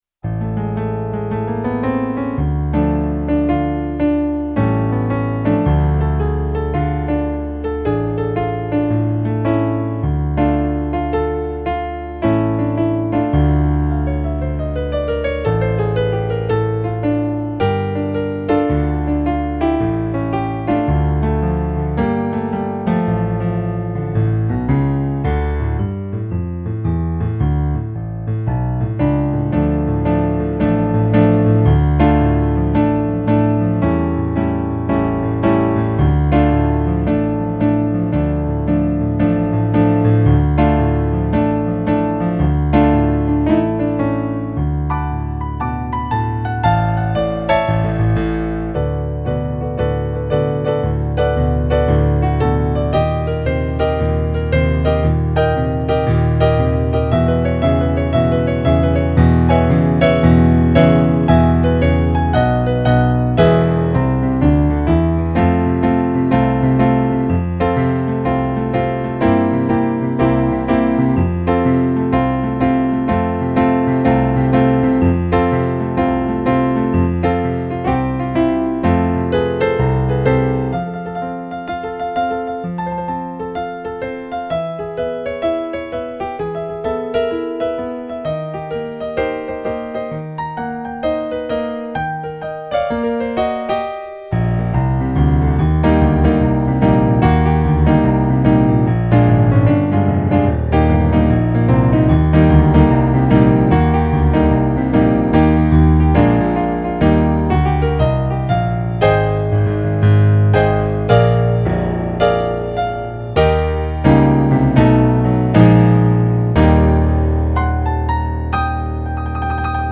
eight piano solo arrangements.  31 pages.
boogie-woogie remix